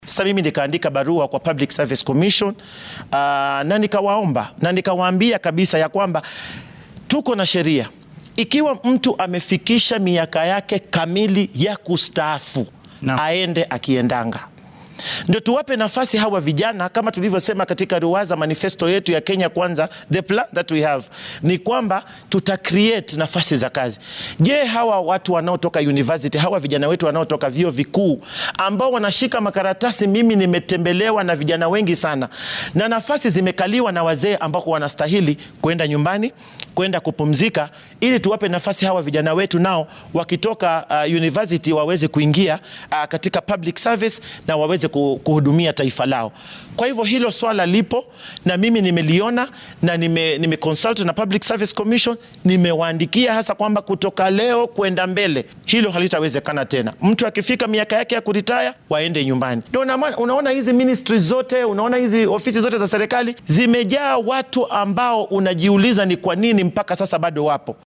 Aisha Jumwa oo wareysi gaar ah siinaysay telefishinka maxalliga ee Citizen ayaa carrabka ku adkeysay inay jiraan dhallinyaro badan oo jaamacadaha wadanka dhameeyay oo shaqo la’aan ah sidaas awgeedna loo baahan yahay in shakhsiyaadka shaqooyinka ku waayeeloobay ay iska nastaan.
Mar la waydiiyay in arrintan ay ku guulaysan doonto ayay wasiirka adeegga dadweynaha ku jawaabtay sidatan.
Aisha-Jumwa.mp3